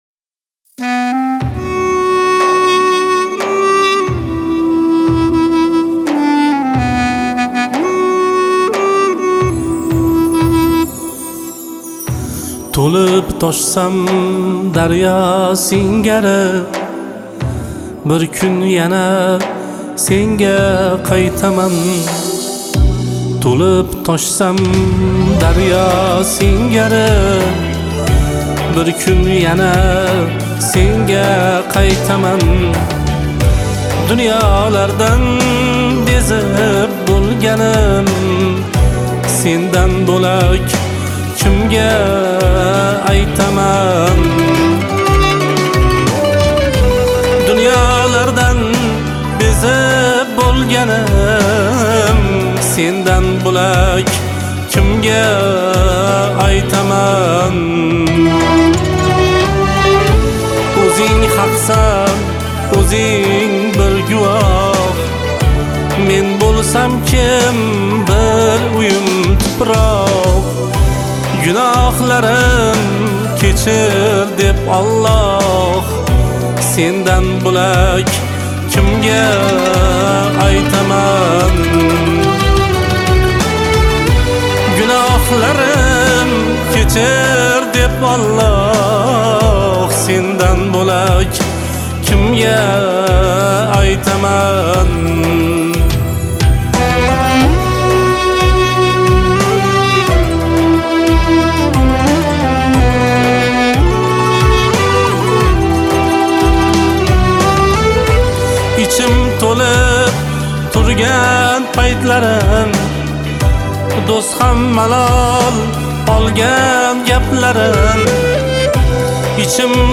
Кавказская музыка